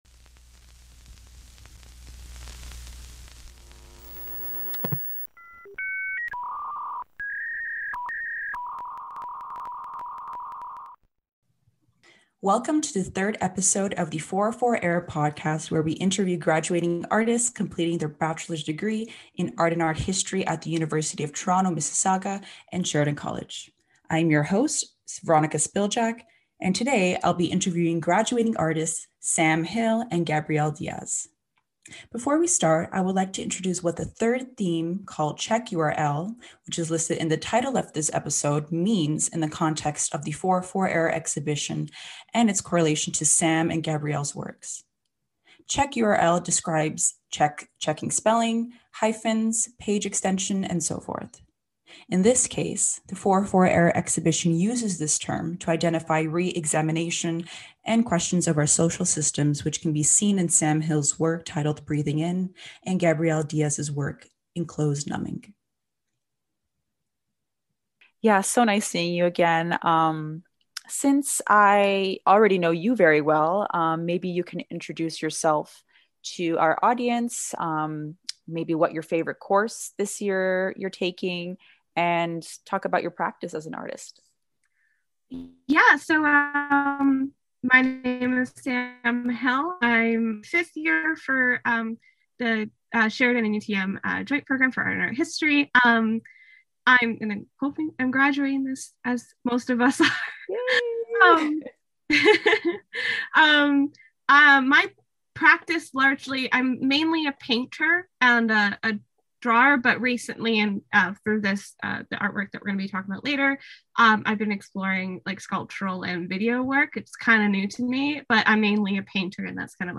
Throughout this podcast series, we’ll be interviewing graduating artists completing their bachelor’s degrees in Art and Art History in the joint University of Toronto Mississauga and Sheridan College program.